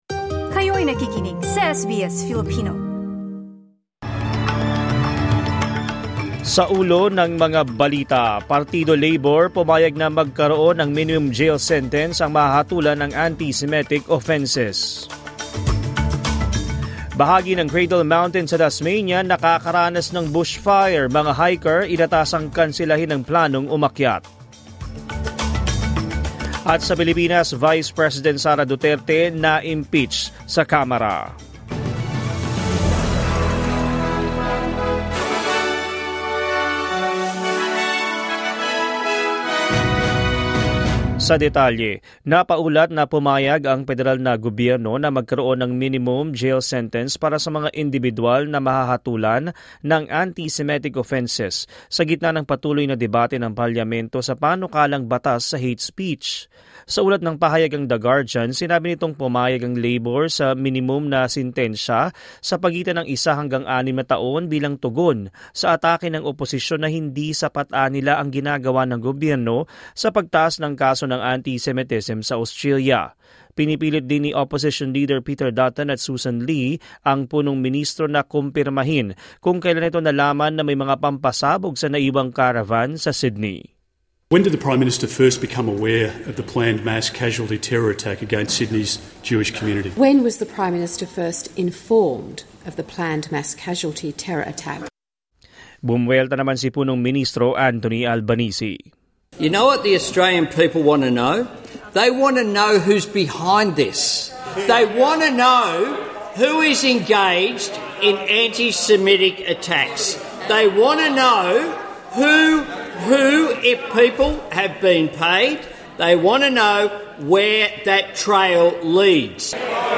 SBS News in Filipino, Thursday 6 February 2025